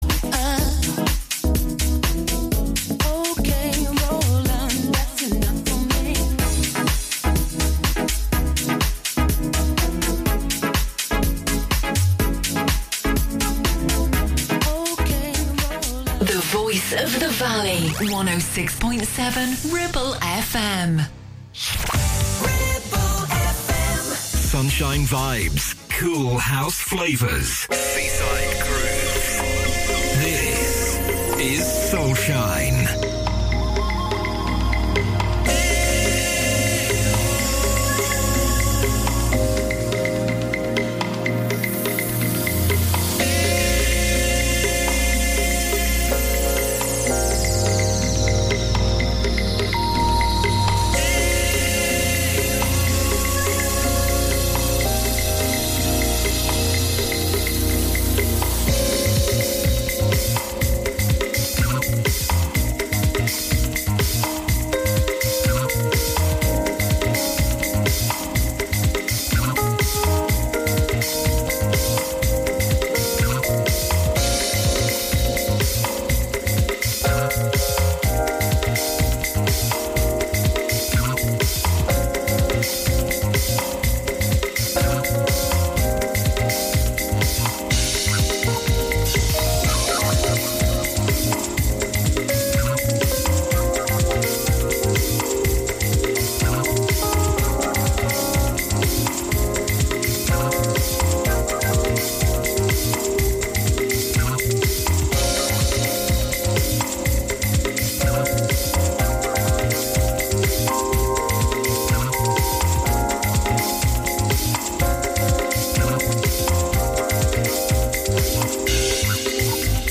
House, Disco, Funk and Soul, its got the lot!